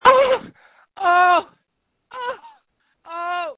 • When you call, we record you making sounds. Hopefully screaming.
You might be unhappy, terrified, frustrated, or elated. All of these are perfectly good reasons to call and record yourself screaming.